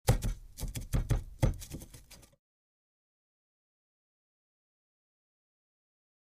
Put Out Cigarette Into Ashtray